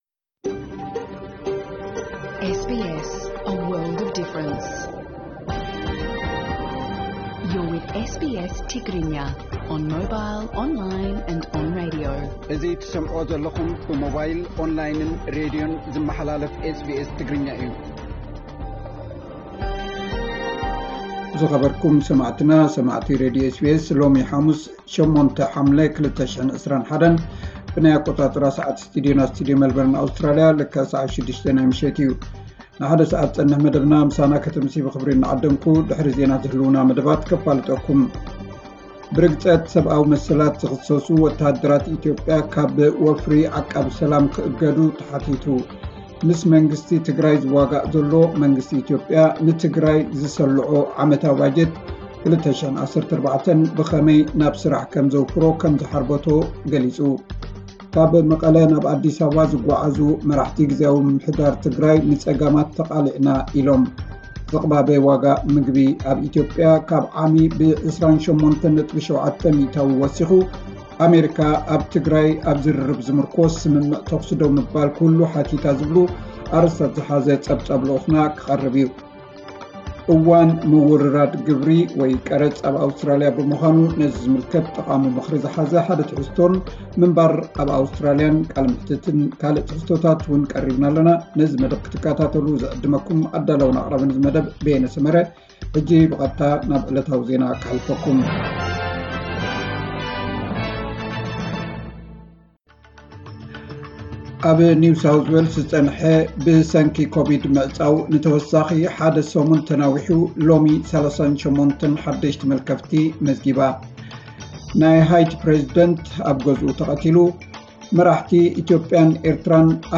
መራሕቲ ኢትዮጵያን ኤርትራን ኣብ ናይ ዓለም ቤት ፍርዲ ብገበን ክኽሰሱ ፕረሲደንት ክልል ትግራይ ጸዊዑ። (ዕለታዊ ዜና)